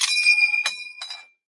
描述：这是一个近距离立体声录音，是M1加兰德弹夹空弹时发出的著名的"ding"。
之后，我用滤波器、混响器、压缩器/限幅器等对录制的声音进行清理和改进。简而言之，这个声音并不是我真正实地录制的，而是从YouTube视频中录制的。然而，我认为我对这个样本做得很好（例如，我去掉了一些背景噪音、声音和脚步声）。
Tag: 步枪 二战 加兰德 武器 战争 射击